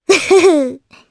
Requina-Vox_Happy1_jp.wav